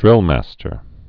(drĭlmăstər)